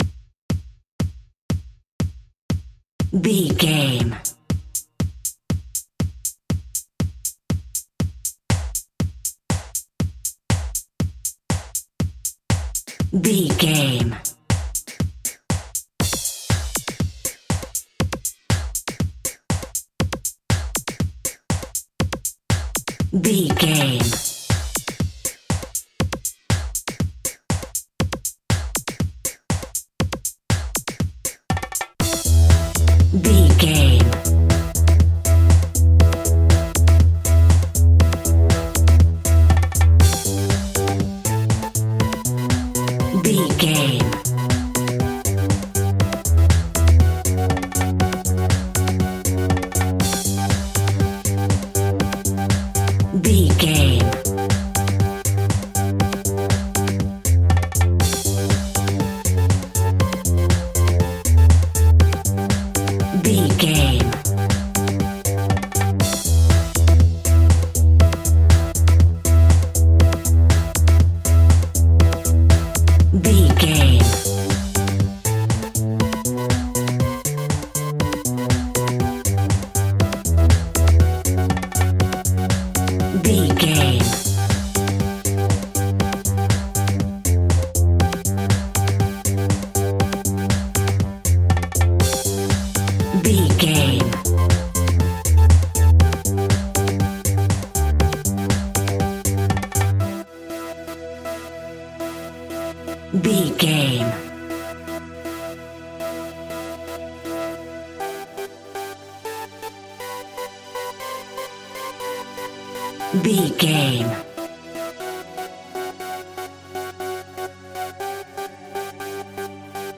Aeolian/Minor
Fast
groovy
smooth
futuristic
drum machine
synthesiser
percussion
tabla
techno
electro house